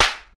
Slap4.wav